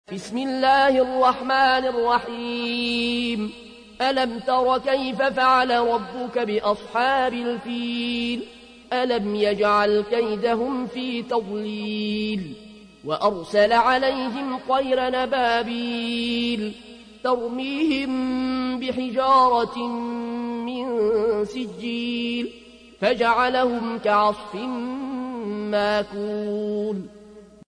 تحميل : 105. سورة الفيل / القارئ العيون الكوشي / القرآن الكريم / موقع يا حسين